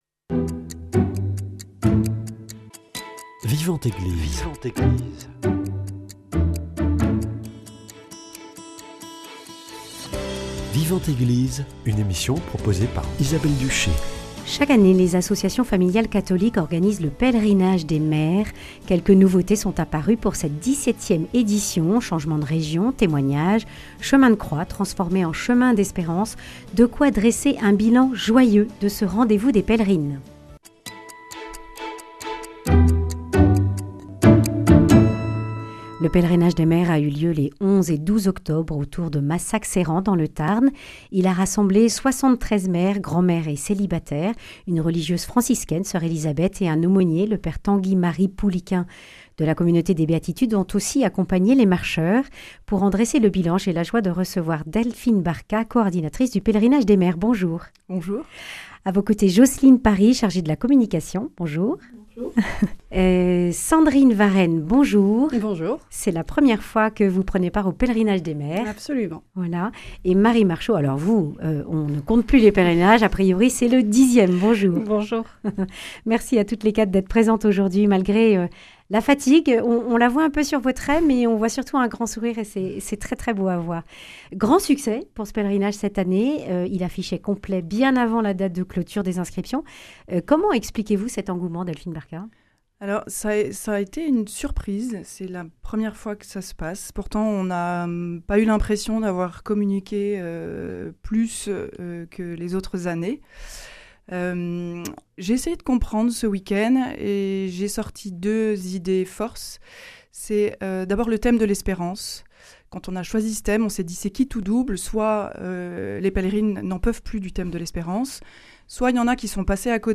Les 11 et 12 octobre, plus de 70 femmes se sont retrouvées pour le pèlerinage des mères, organisé par les AFC. Marche, prière, bienveillance, espérance, fraternité sont les mots que l’on peut retenir de cette 15e édition. Quatre pèlerines en dressent le bilan